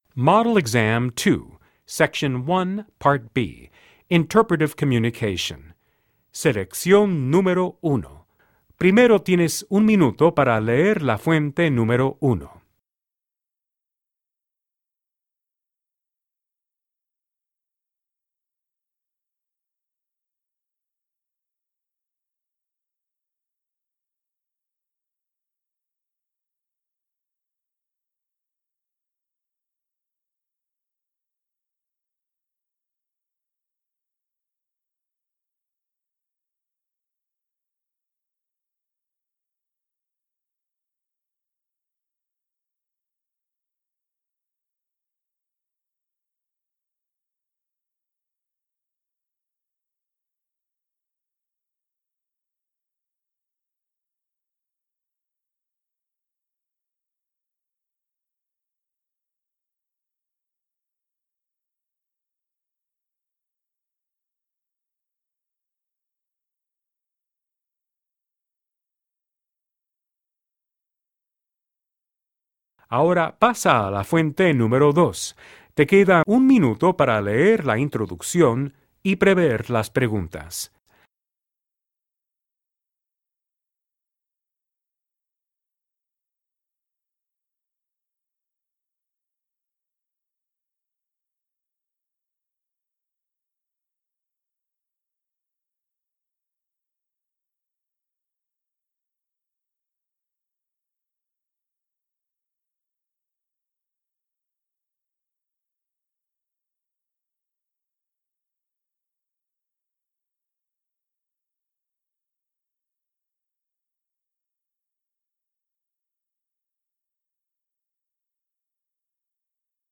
Genre: Alternative.